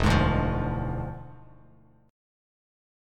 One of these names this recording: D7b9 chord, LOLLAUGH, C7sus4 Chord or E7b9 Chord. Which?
E7b9 Chord